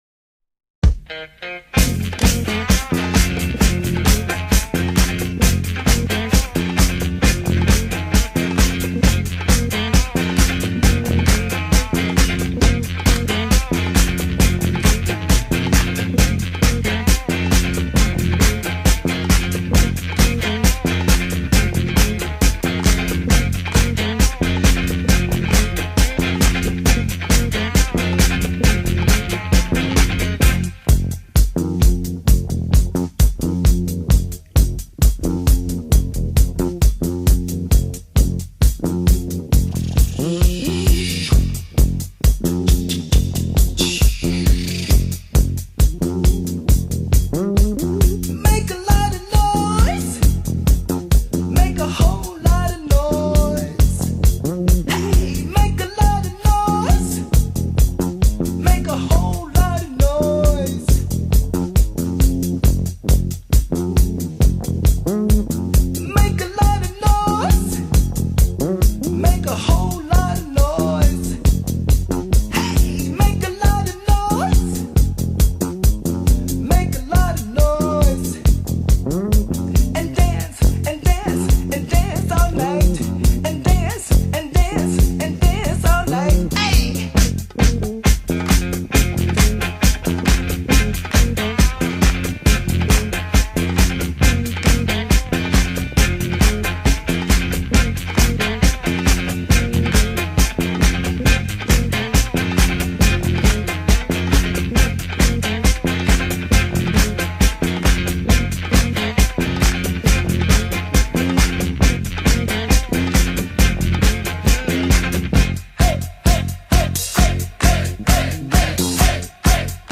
but as a string-swept, four-to-the-floor disco funk number.
With the thick soup of guitar, bass and drums at its core